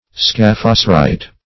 Search Result for " scaphocerite" : The Collaborative International Dictionary of English v.0.48: Scaphocerite \Scaph`o*ce"rite\, n. [Gr. ska`fh boat + E. cerite.]
scaphocerite.mp3